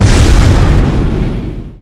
Expl04.ogg